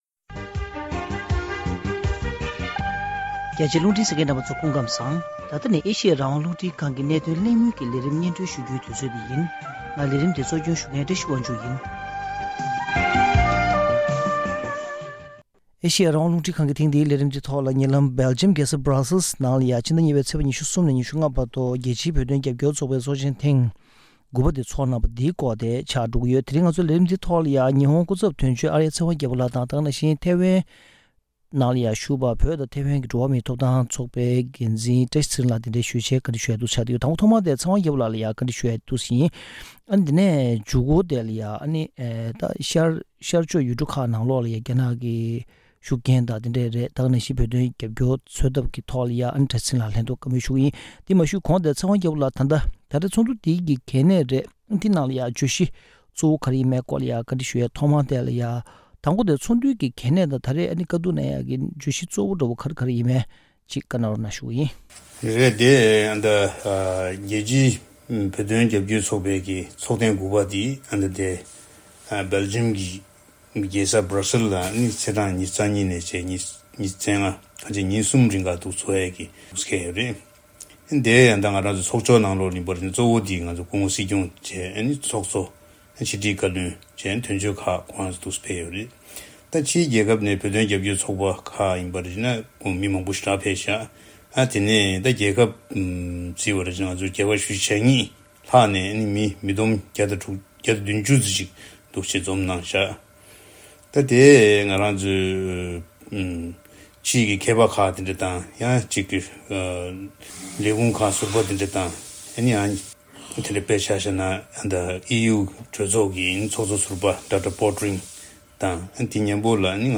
རྒྱལ་སྤྱིའི་བོད་དོན་རྒྱབ་སྐྱོར་ཚོགས་པའི་ཚོགས་ཆེན་ཐེངས་ ༩ པའི་གྲུབ་དོན་དང་མ་འོངས་རྒྱལ་སྤྱི་དང་ཤར་ཨེ་ཤེ་ཡའི་ནང་ལས་དོན་གནང་འཆར་སོགས་ཀྱི་སྐོར་གླེང་མོལ།